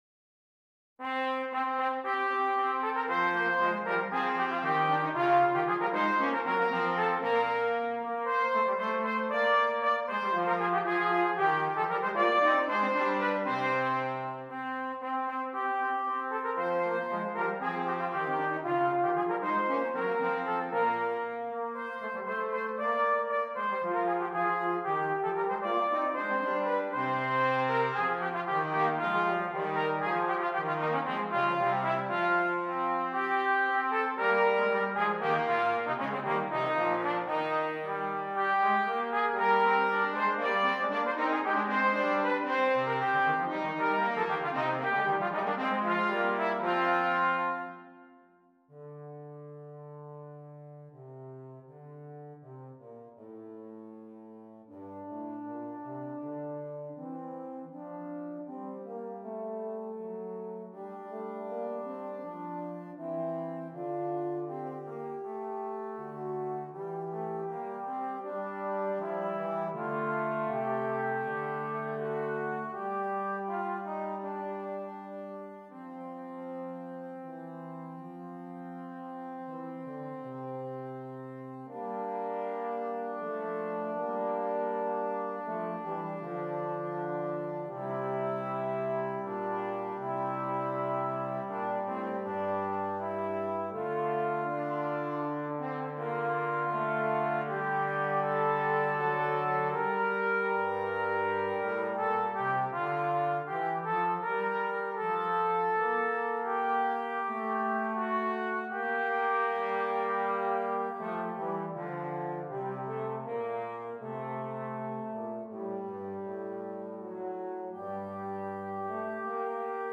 Brass Trio